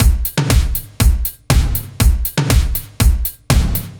Index of /musicradar/french-house-chillout-samples/120bpm/Beats
FHC_BeatB_120-01.wav